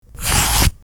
pencil-cross.mp3